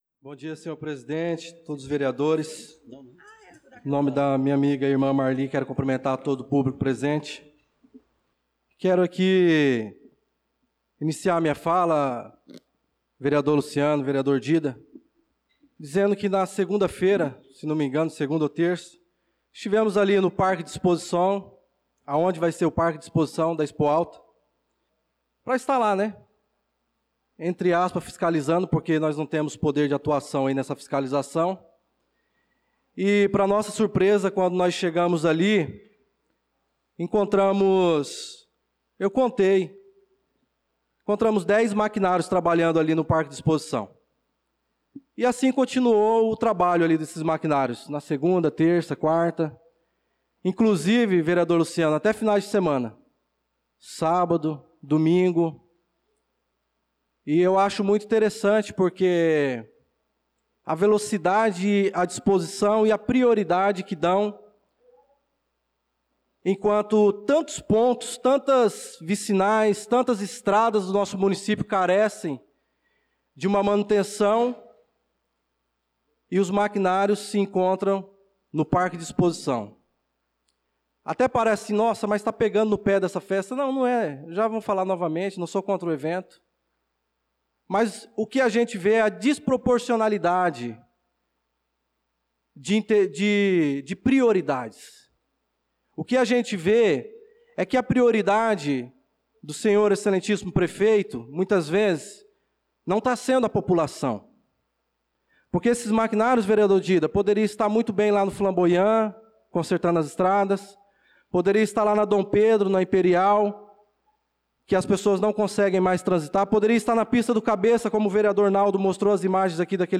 Pronunciamento do vereador Darlan Carvalho na Sessão Ordinária do dia 05/05/2025